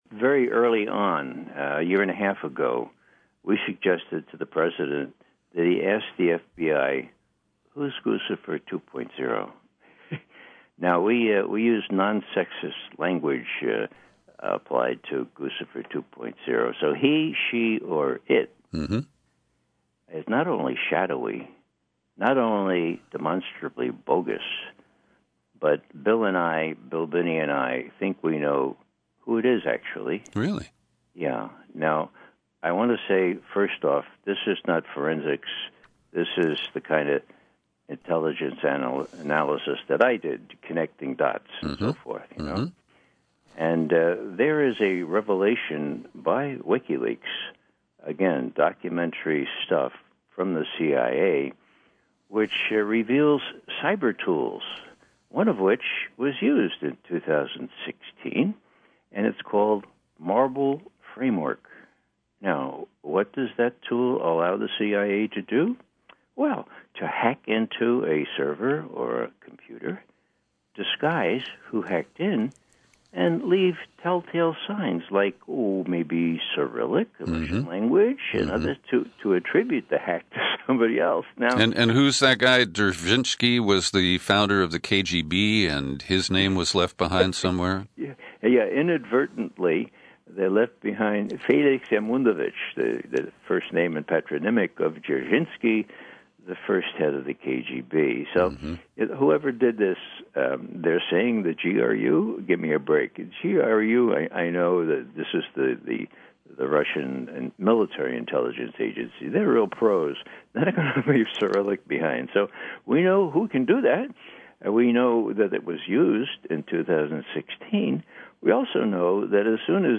In-Depth Interview: Ex-CIA Analyst Ray McGovern Confronts Clapper, Suspects Brennan, Defends Assange